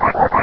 Cri de Chamallot dans Pokémon Rubis et Saphir.